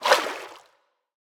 Minecraft Version Minecraft Version snapshot Latest Release | Latest Snapshot snapshot / assets / minecraft / sounds / entity / fish / swim7.ogg Compare With Compare With Latest Release | Latest Snapshot
swim7.ogg